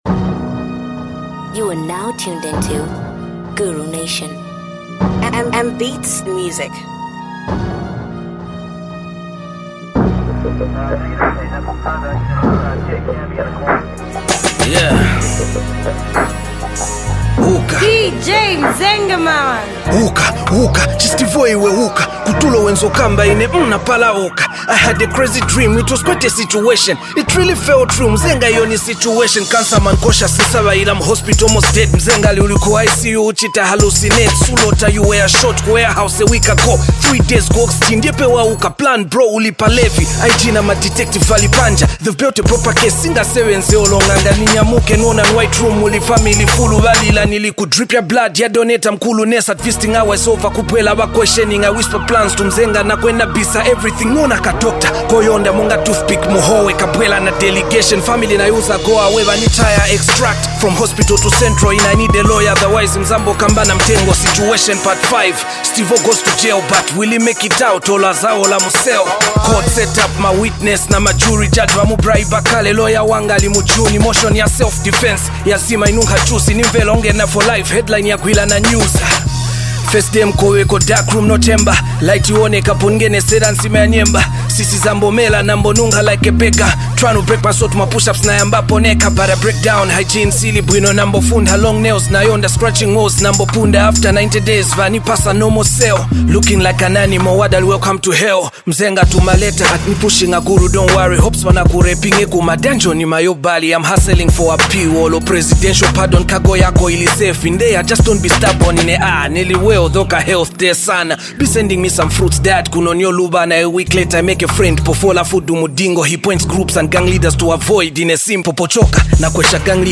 Zambian rapper